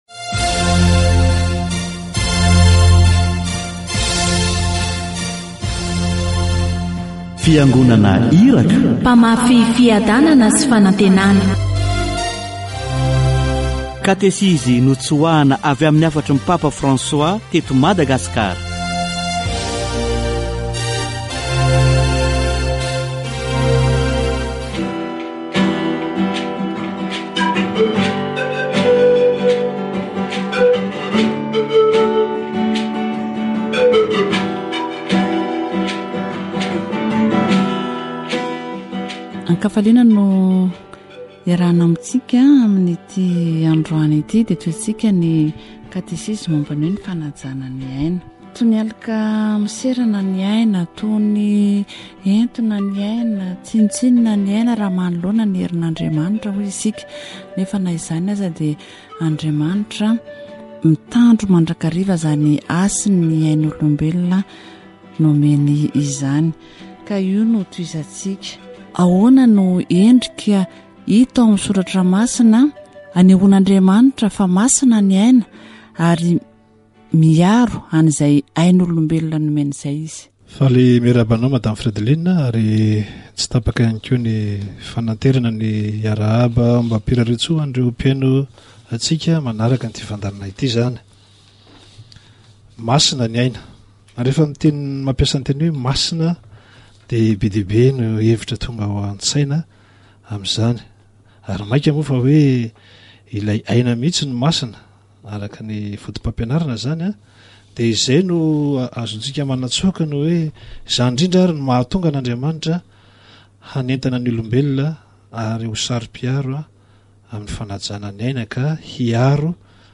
Ny fanajana ny aina dia hita taratra ao anatin'ny didy folo : aza mamono olona.  Katesizy momba ny fanajana ny aina